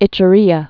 (ĭchə-rēə)